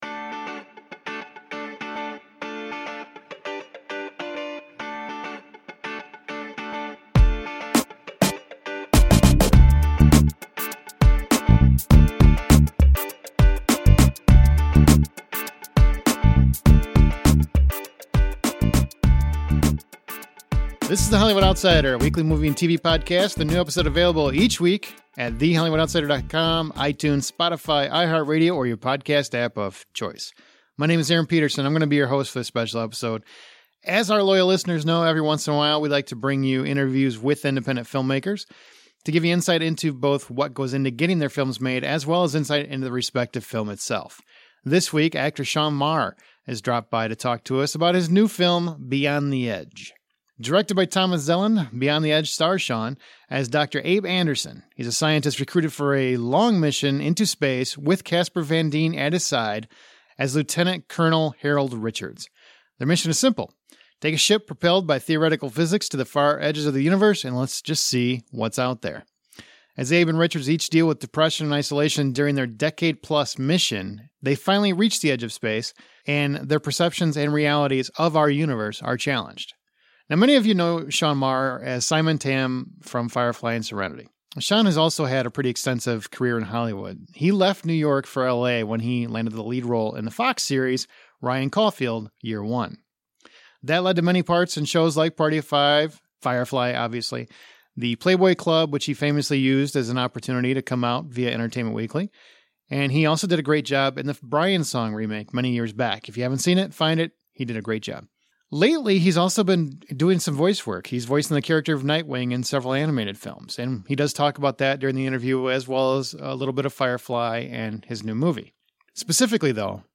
Interview with Sean Maher | Beyond The Edge